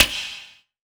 SNARE 015.wav